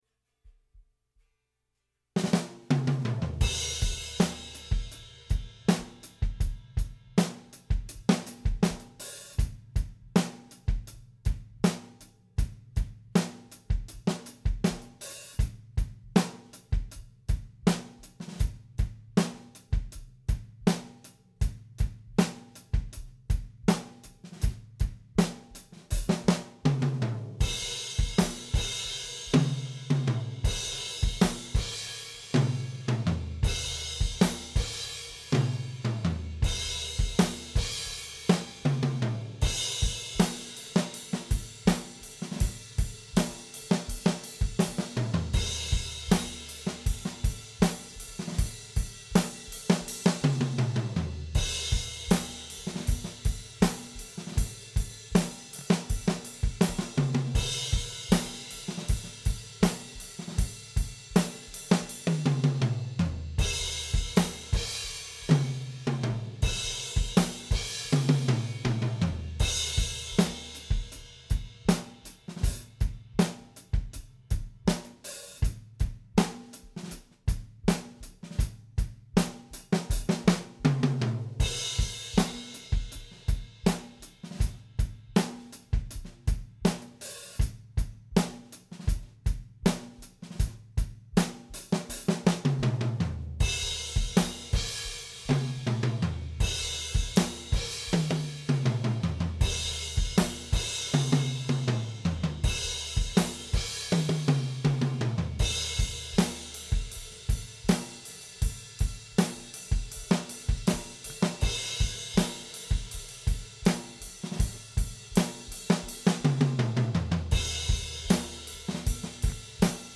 thought you guys might like to hear some drums I recorded last night..
this is the drum track for my bands latest song. There is no EQ, compression or reverb on any of the individual tracks. Let me know if you want more details ( kit, mics, placement, room, etc ) FYI.. this was more a scratch track/proof of concept.. i'm going back to re-record a tighter version.... not making excuses... I know some kicks and tom runs are slightly out of time.